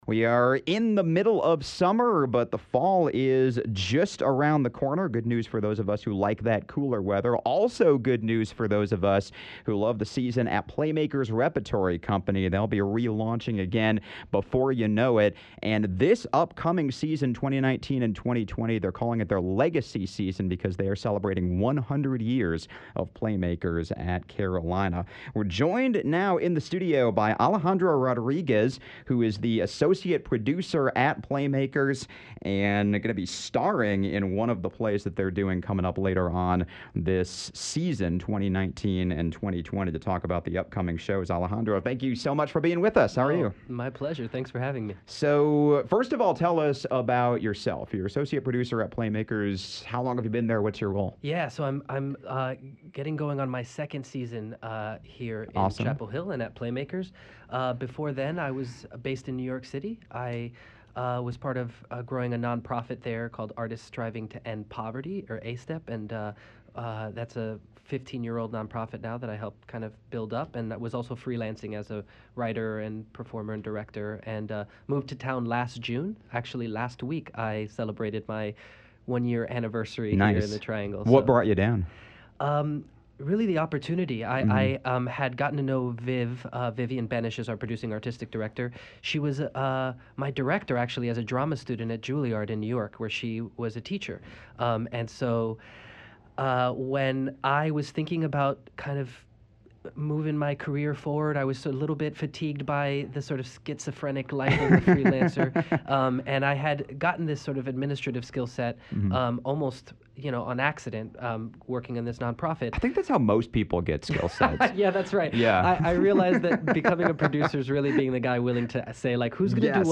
(Conversation sponsored by PlayMakers Repertory Company.)